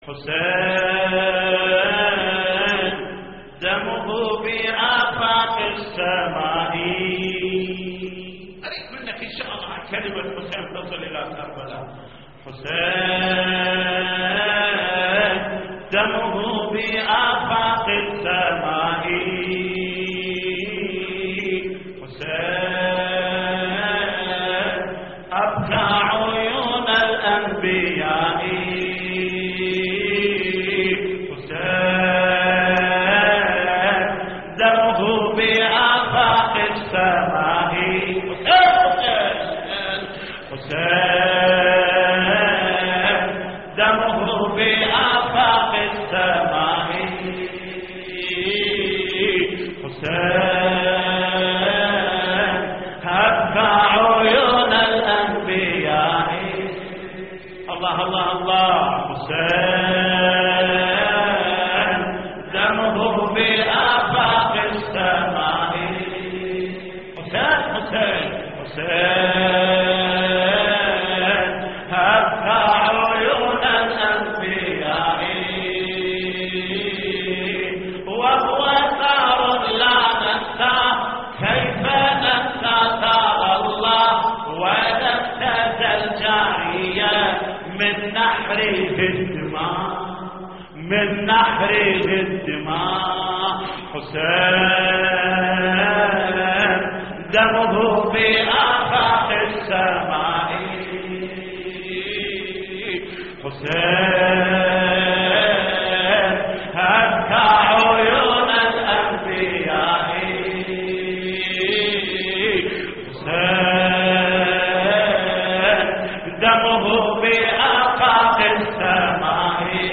تحميل : حسين دمه بآفاق السماء حسين أبكى عيون الأنبياء / الرادود جليل الكربلائي / اللطميات الحسينية / موقع يا حسين